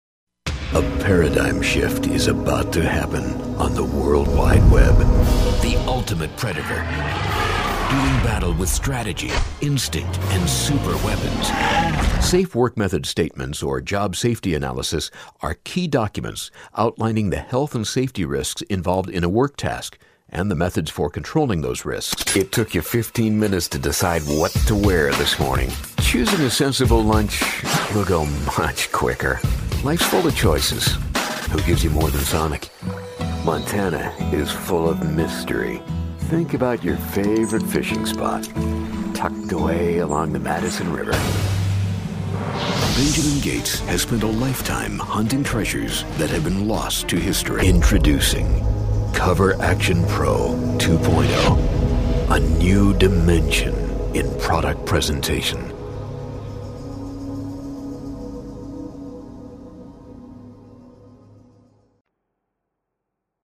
ISDN equipped home studio.
Authoritative, Confident, Professional, Warm
Sprechprobe: Werbung (Muttersprache):